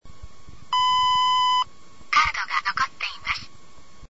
DIU-9000 音声案内　（MP3録音ファイル）